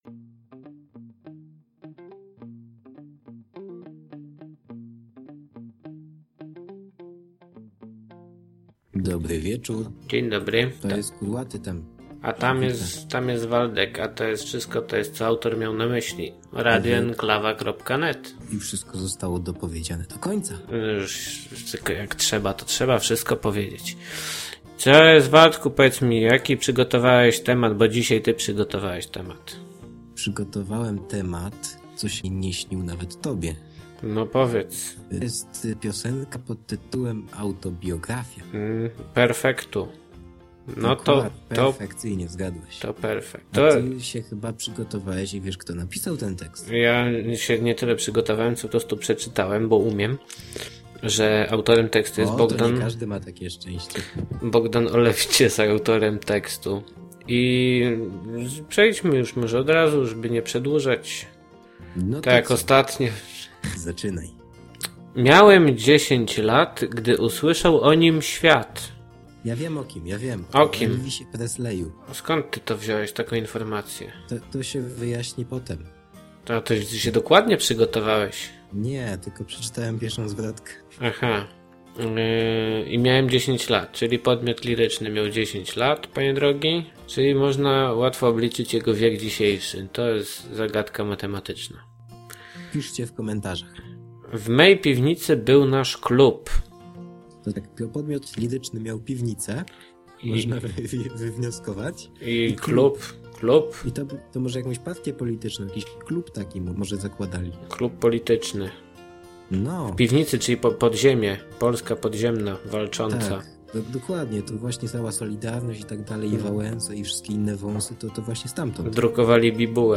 "Co Ałtor Miał na Myśli" to audycja rozrywkowa, nagrywana co tydzień lub dwa.